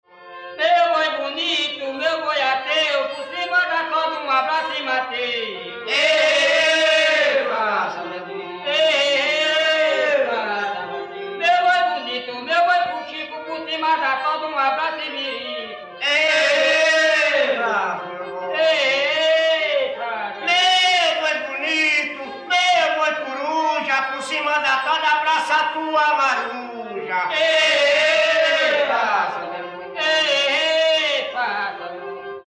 Toada
Atividade musical de caráter lúdico sendo formada por melodias simples apresentadas em forma de estrofe e refrão, geralmente em quadras. Não possui forma fixa e está mais associada à linha melódica do que à peça em si.
toada.mp3